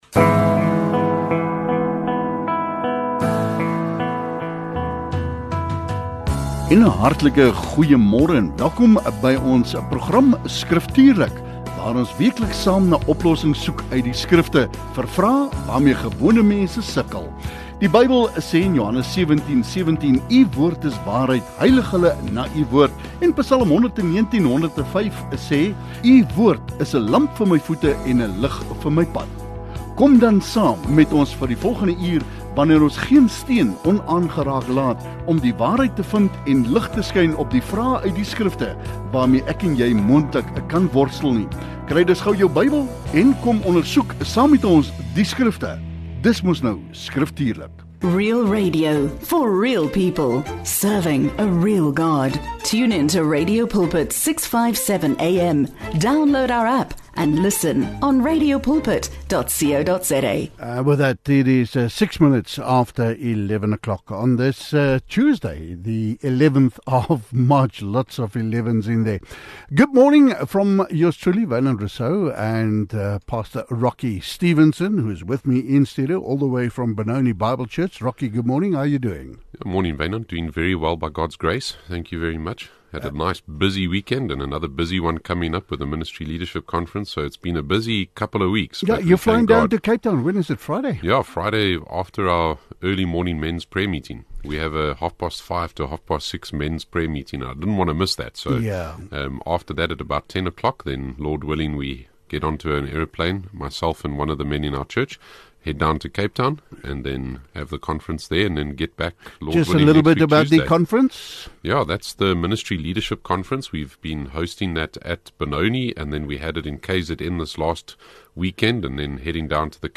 Luisteraars stuur vrae direk na die ateljee via WhatsApp.